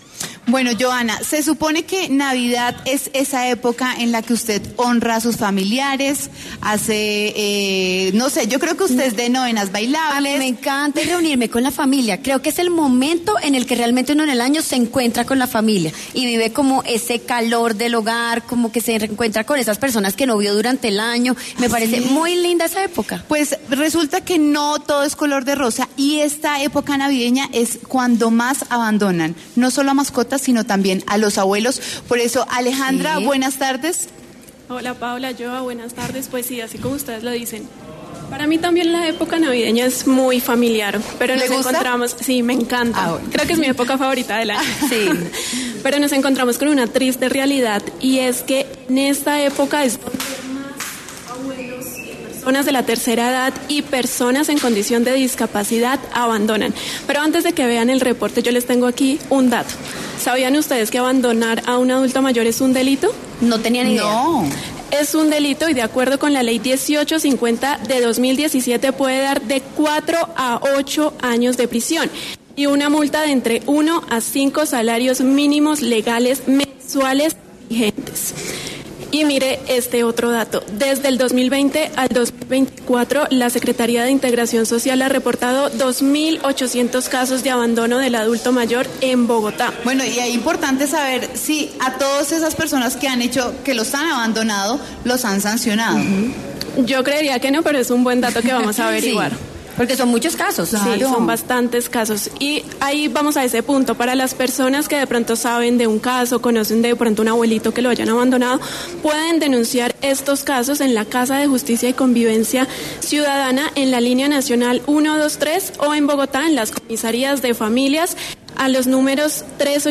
Estos adultos mayores y personas en condición de discapacidad le contaron a W Sin Carreta sus historias.
W Sin Carreta visitó la fundación, ubicada en la localidad de Suba, para conocer el testimonio de algunos de estos adultos mayores.